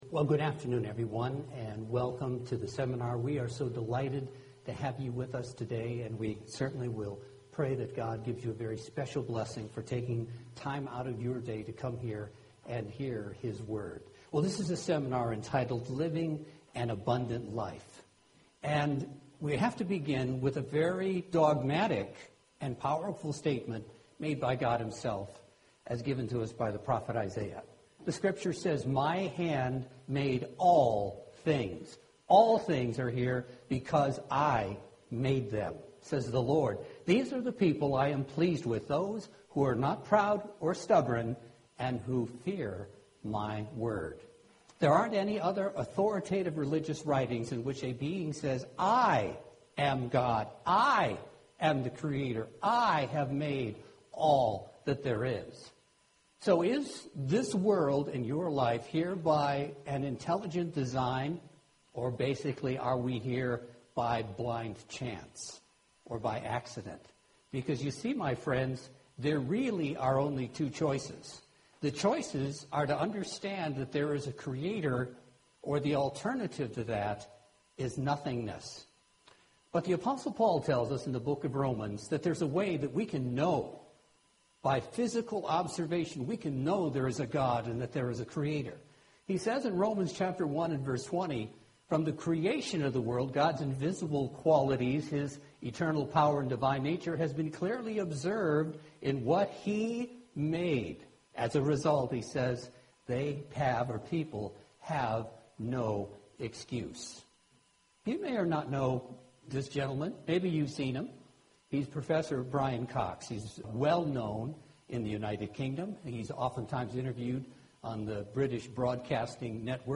So, is the world and your life here by an intelligent plan or design, or is it here by blind chance or an accident? Let's examine this topic in depth through this Kingdom of God seminar and see how we can live our lives to the fullest.